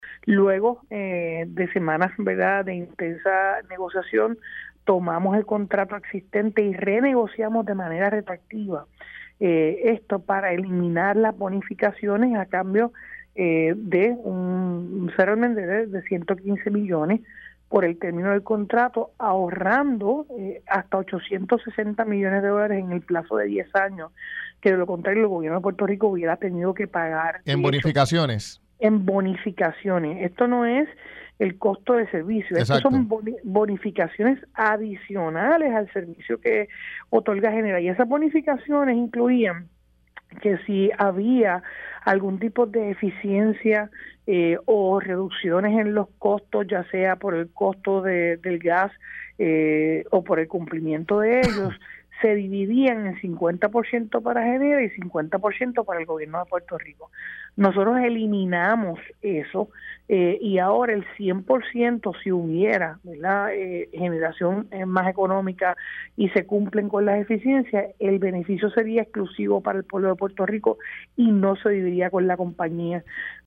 115-JENNIFFER-GONZALEZ-GOBERNADORA-LOGRAN-RENEGOCIACION-DE-CONTRATO-Y-ELIMINAN-BONOFICACIONES-A-GENERA-PR.mp3